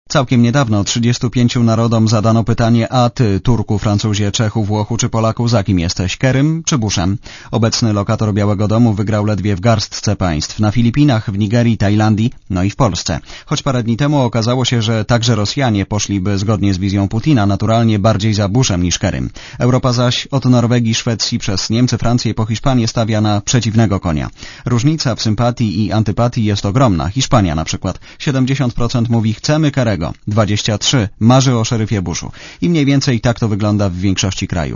Posłuchaj relacji Jarosława Kuźniara